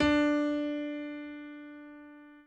b_pianochord_v100l1-2o5d.ogg